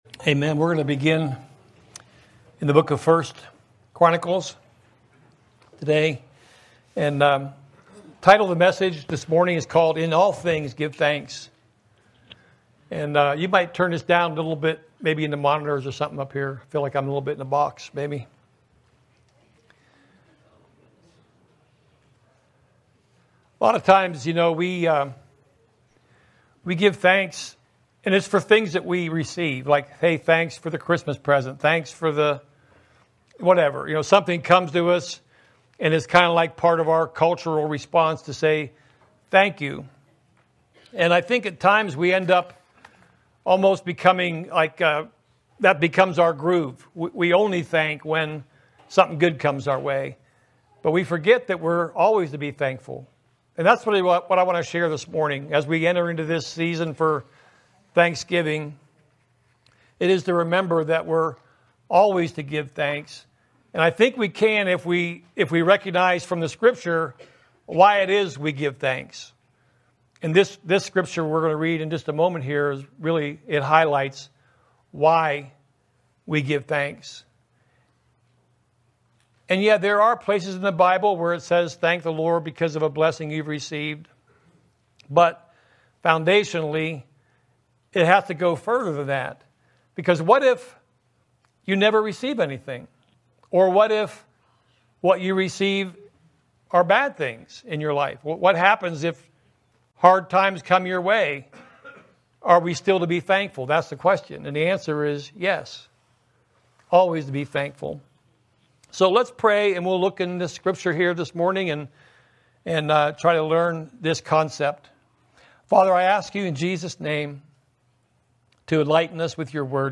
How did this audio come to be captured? From Series: "Sunday Morning Service"